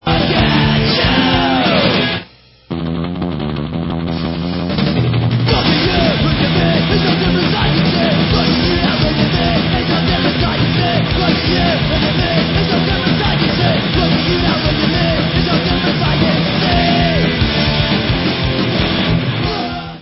sledovat novinky v oddělení Rock/Hardcore